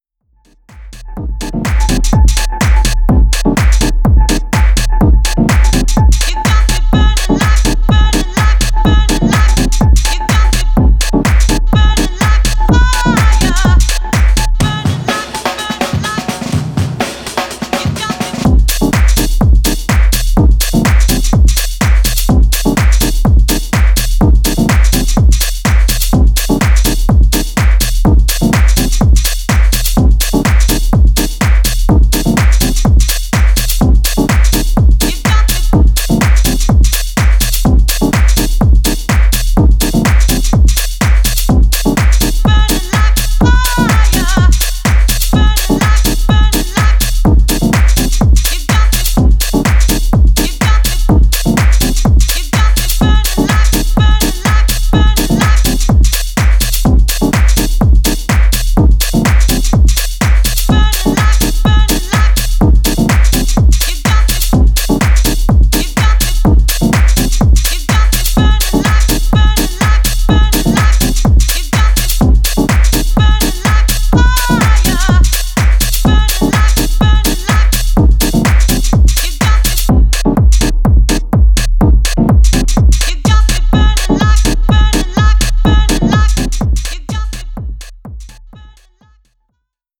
いずれからもオーセンティックな90sハウスへのリスペクトが感じられますね。